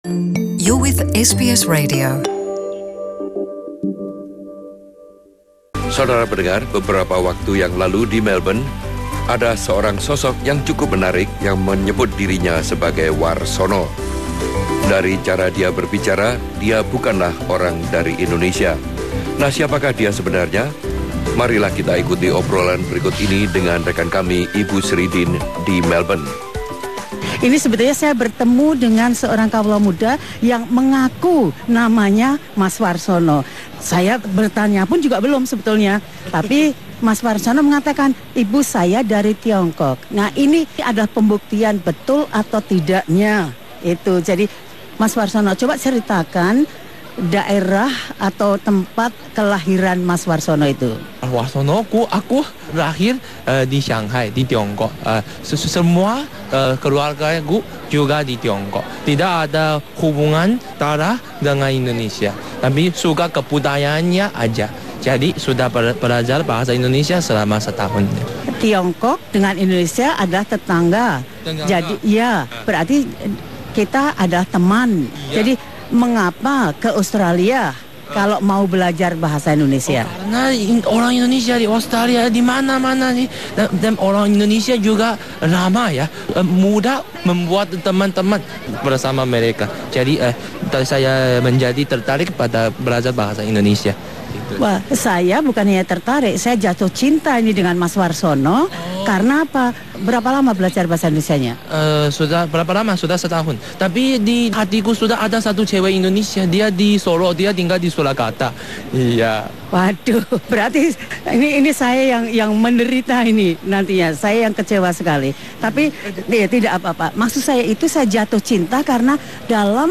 interviewed by SBS at the Indonesian Independence Day celebration at Federation Square, Melb 17 Aug 2018.